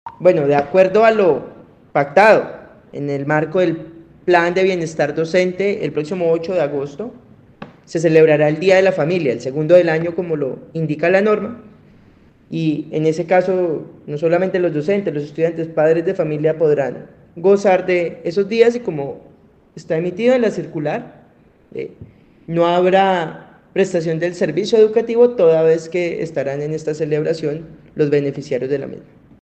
Nicolás Ordoñez, secretario de educación de Santander